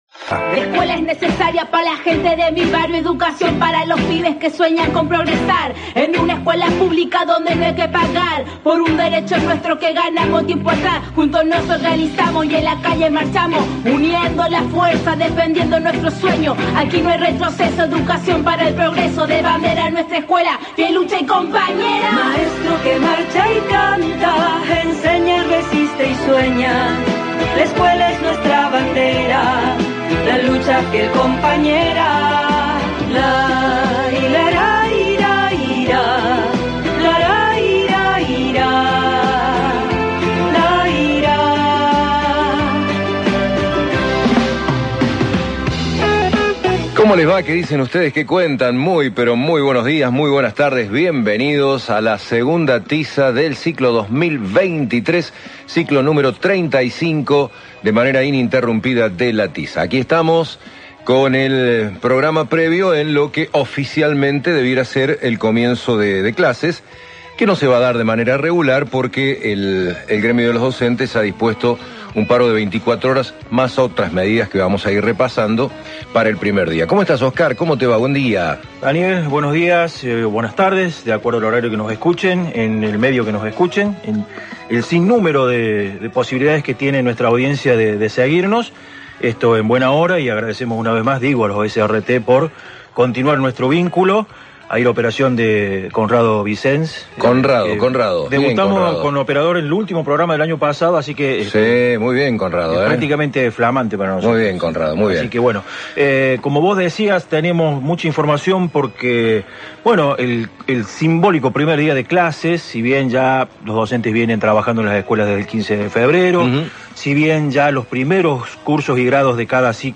El Sindicato cuenta con el programa de radio "La Tiza", en el cual se reflejan las temáticas gremiales, educativas y pedagógicas que interesan a los y las docentes.
El programa se emite los sábados de 12 a 13 hs por radio Universidad (AM 580) y de 16 a 17 hs por Más que música (FM 102.3).